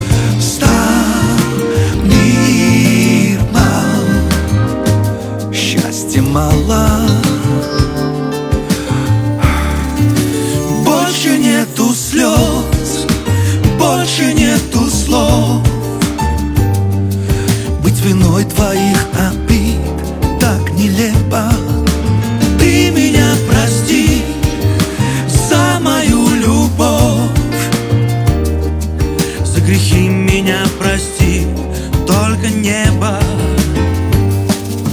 поп
душевные